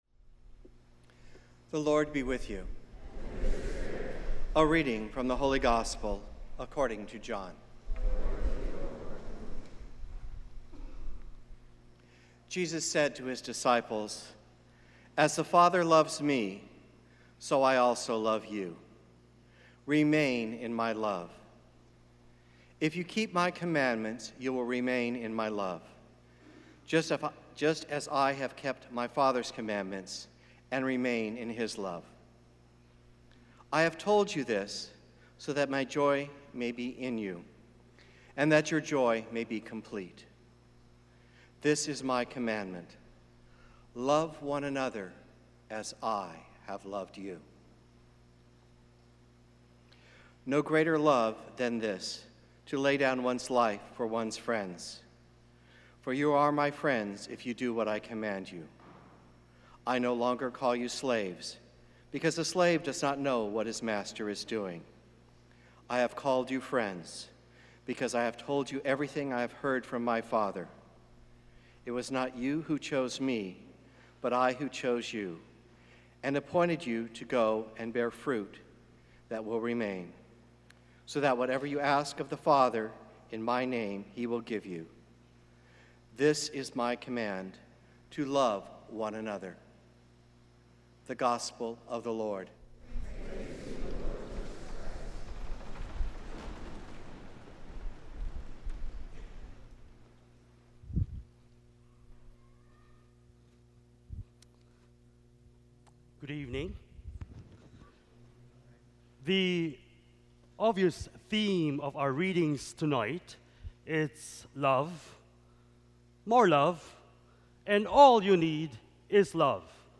Gospel and Homily Podcasts